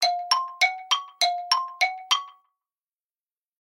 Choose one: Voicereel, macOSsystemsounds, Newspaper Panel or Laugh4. macOSsystemsounds